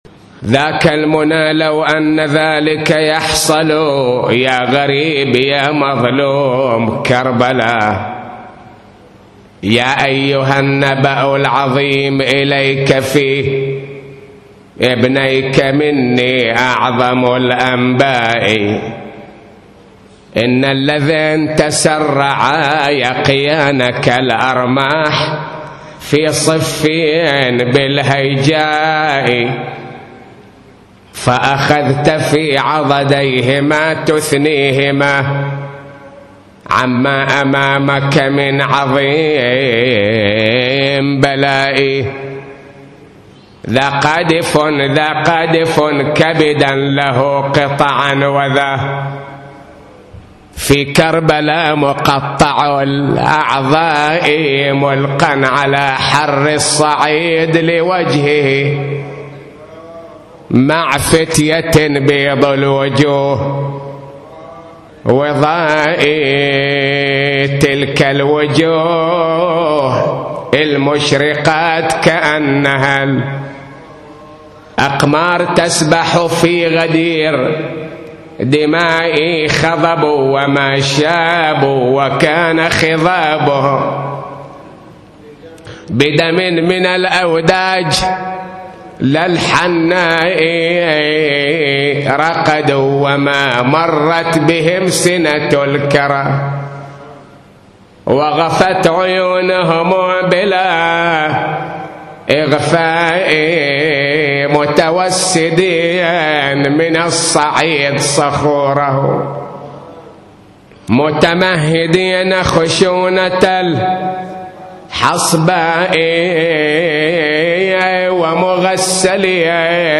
نواعي وأبيات حسينية – 9